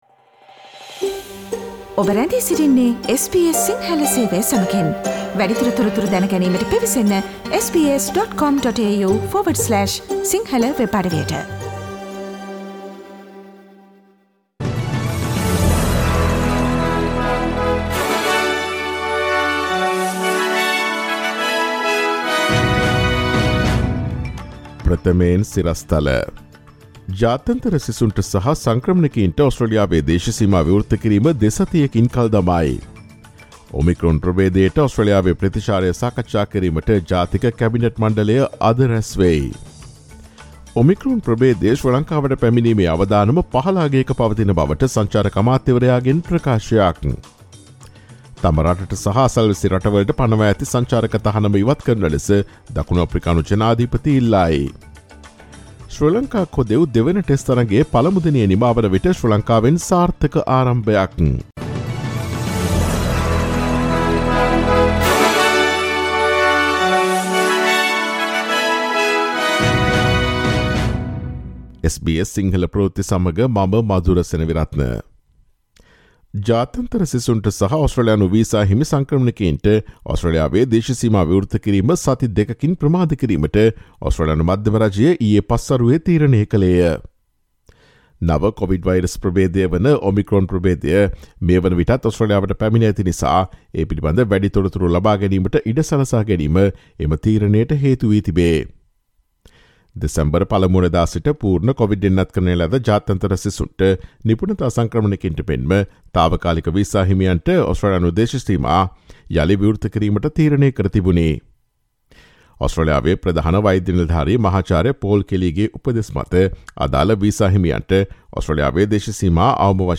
ඔස්ට්‍රේලියාවේ සහ ශ්‍රී ලංකාවේ නවතම පුවත් මෙන්ම විදෙස් පුවත් සහ ක්‍රීඩා පුවත් රැගත් SBS සිංහල සේවයේ 2021 නොවැම්බර් 30 වන දා අඟහරුවාදා වැඩසටහනේ ප්‍රවෘත්ති ප්‍රකාශයට සවන් දීමට ඉහත ඡායාරූපය මත ඇති speaker සලකුණ මත click කරන්න.